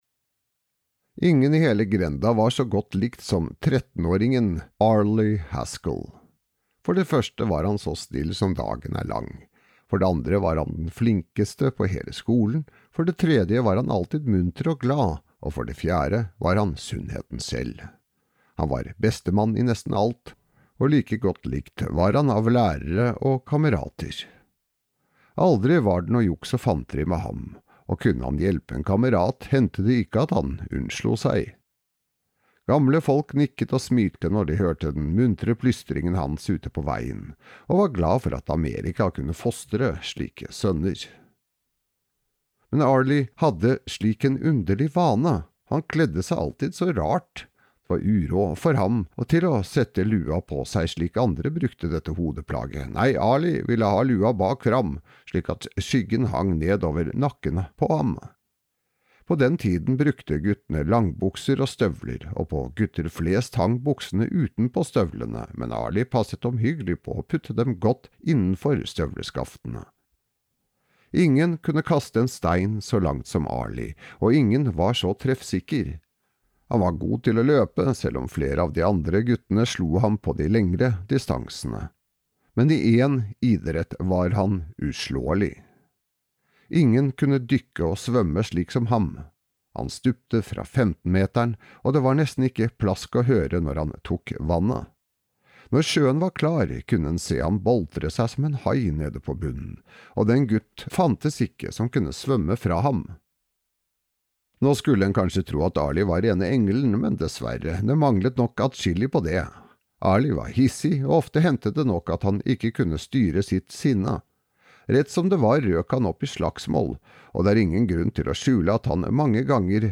Indianerskatten (lydbok) av Edward S. Ellis